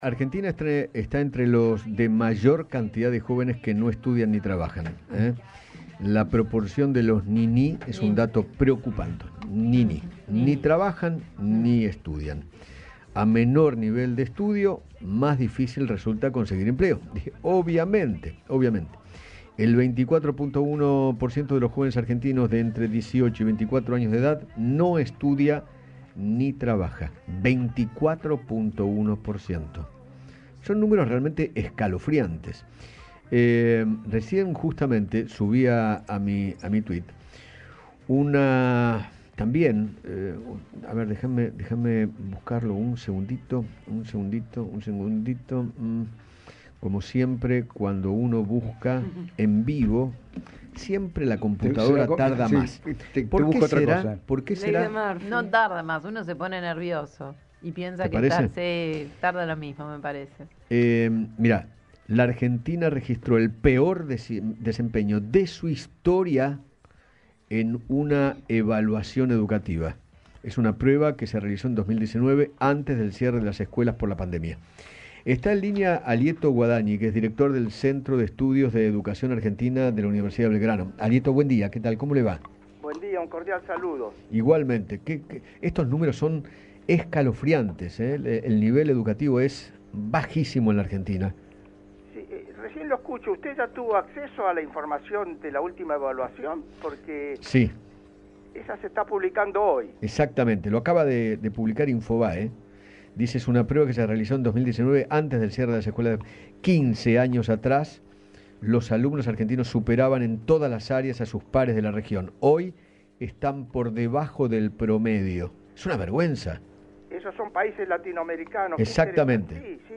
Alieto Guadagni, director del Centro de Estudios de la Educación Argentina de la Universidad de Belgrano, habló con Eduardo Feinmann sobre el bajo nivel educativo de los jóvenes y se refirió a los resultados que registró la Argentina en una evaluación internacional de aprendizaje de la Unesco.